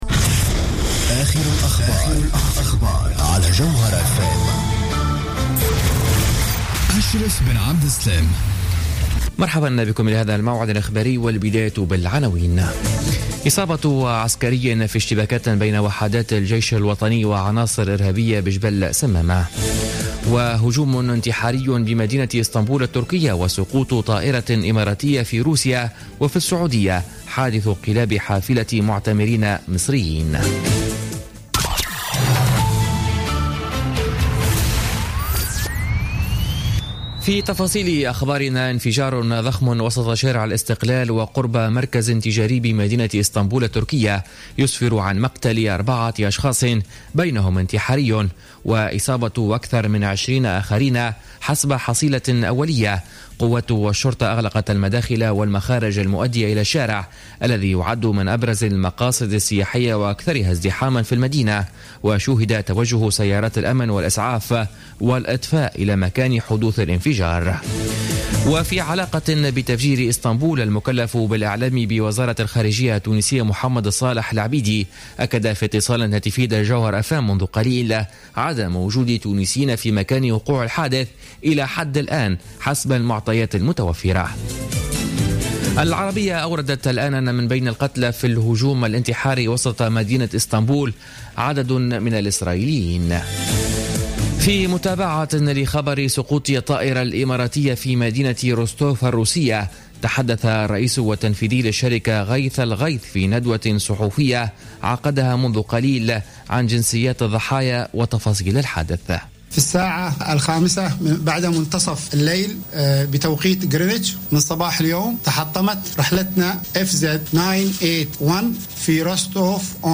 نشرة أخبار منتصف النهار 19 مارس 2016